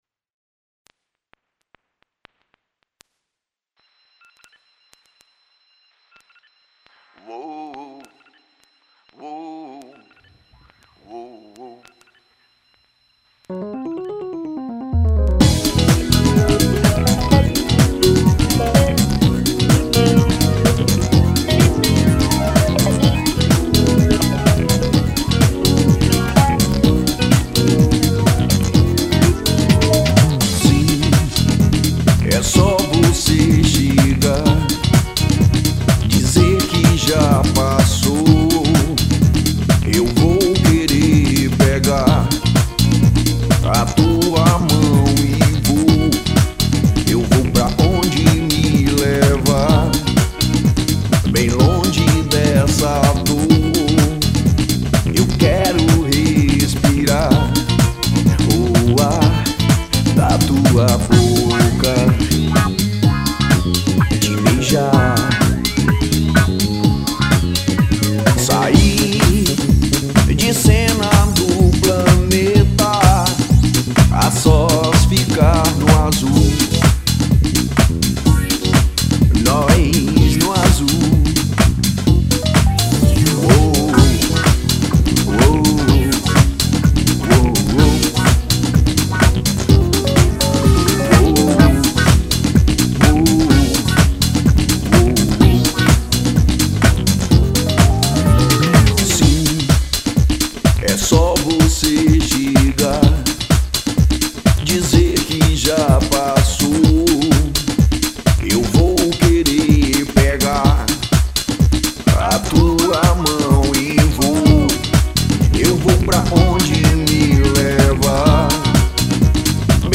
1965   03:22:00   Faixa:     Rock Nacional